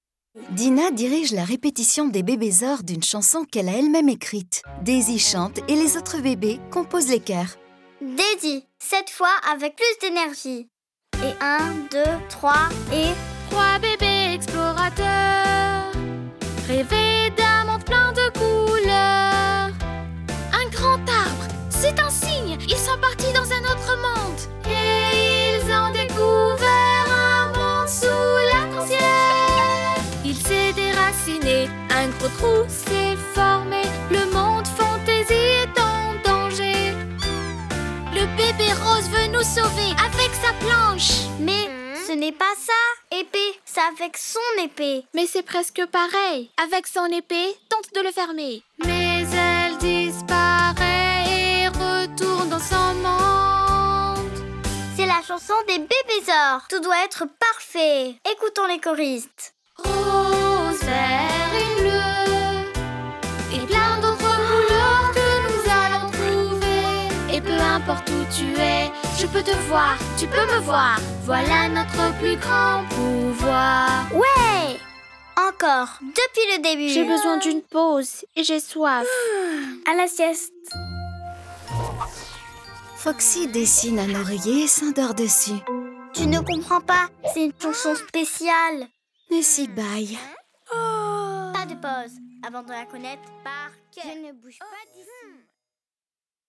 2023-04-04 Amusez-vous à écouter cette compilation d'histoires magiques pour garçons et filles ! Télescopes magiques, nouvelles disparitions, chats qui parlent...